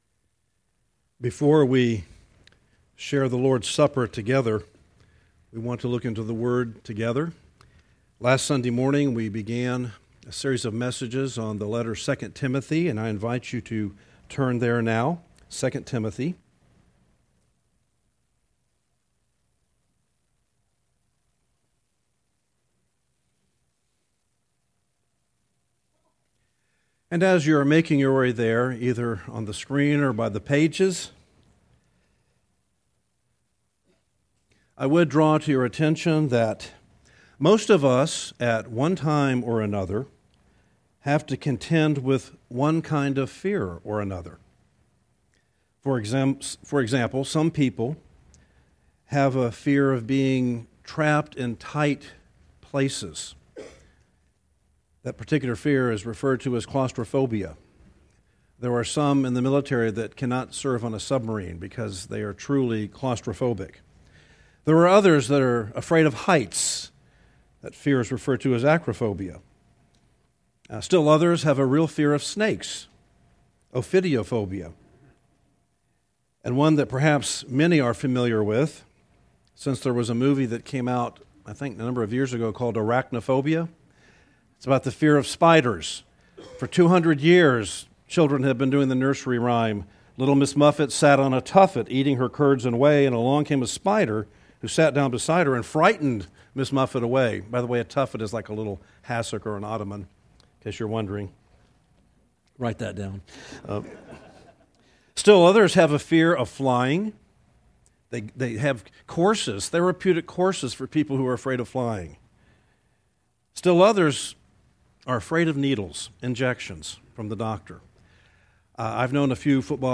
teaches from the series: 2 Timothy, in the book of 2 Timothy, verses 1:6 - 1:8